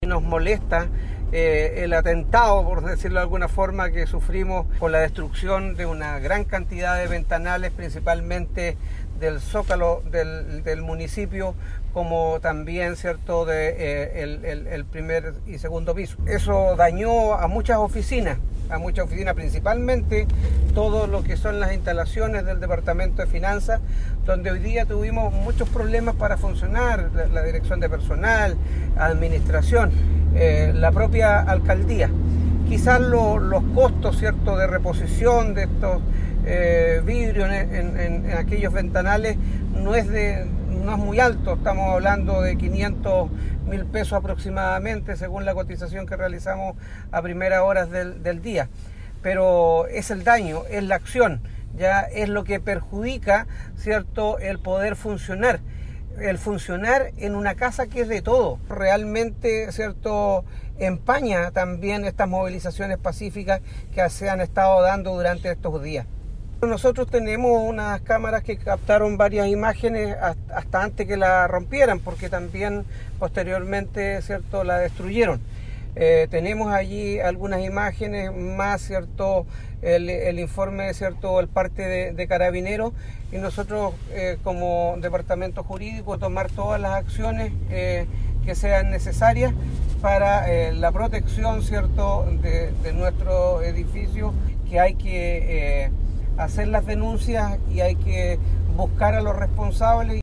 Sobre la situación que afectó al edificio municipal se refirió el alcalde de Ancud Carlos Gómez, rechazando de plano estas acciones de violencia y anunció las acciones legales que correspondan para perseguir las responsabilidades.